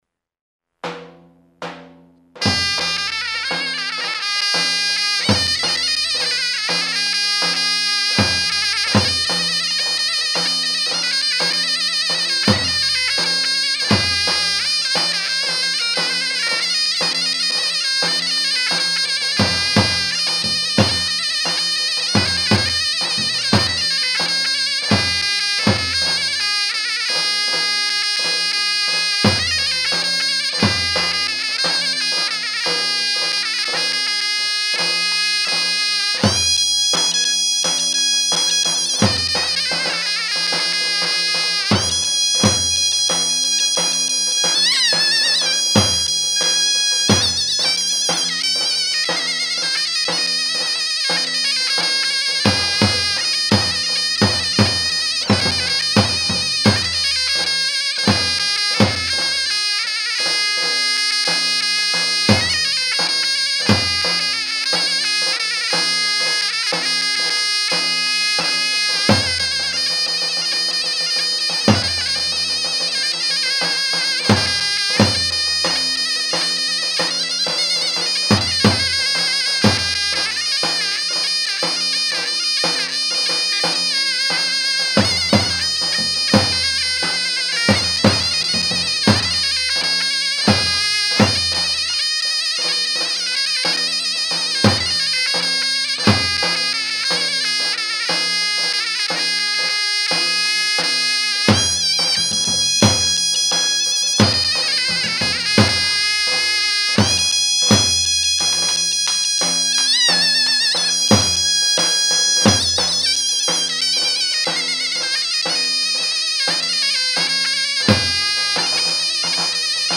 Kategori Sözlü, Sözsüz Yöresel Müzikler